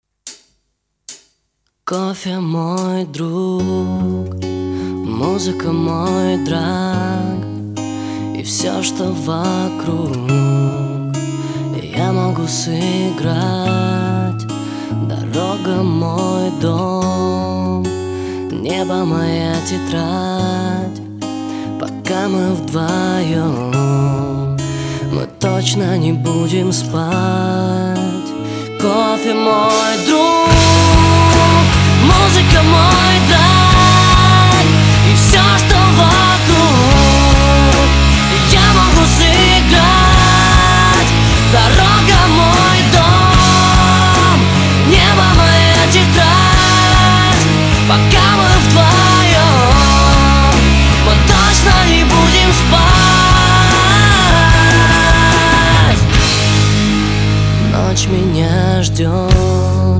мужской вокал
спокойные
нарастающие
Alternative Rock
Pop Rock
лирические